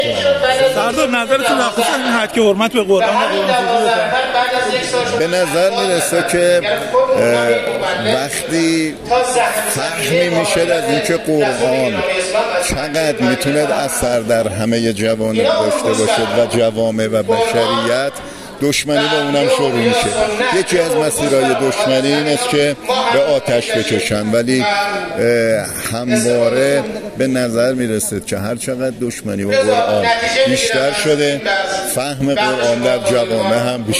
سردار احمدرضا رادان، فرمانده کل انتظامی جمهوری اسلامی ایران، در گفت‌وگو با ایکنا، درباره هتک حرمت قرآن و قرآن‌سوزی‌های اخیر گفت: کسانی که این اقدامات را انجام می‌دهند،‌ به این نتیجه رسیده‌اند که قرآن در همه جوانب، جوامع و بشریت اثر ملموسی دارد و سپس دشمنی با آن‌ را شروع می‌کنند و یکی از شیوه‌های نشان دادن این دشمنی‌ها، به آتش کشیدن است.